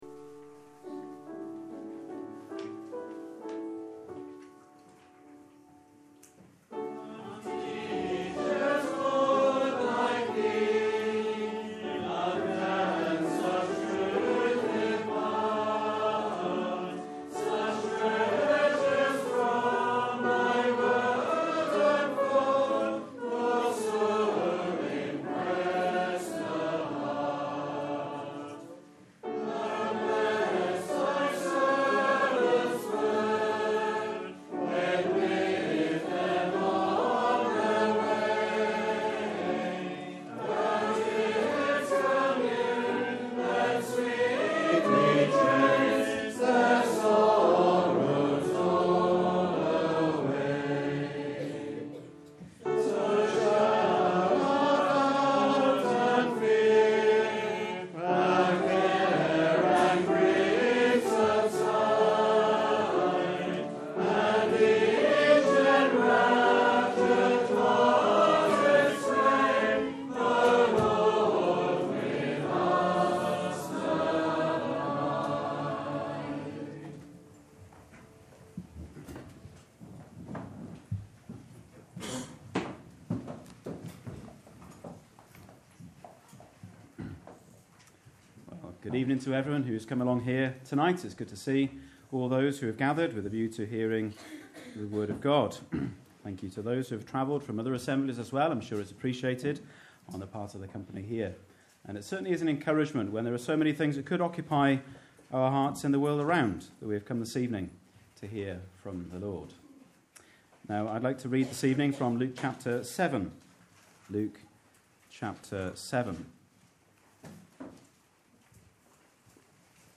A recording of our Regular Saturday Night Ministry Meeting.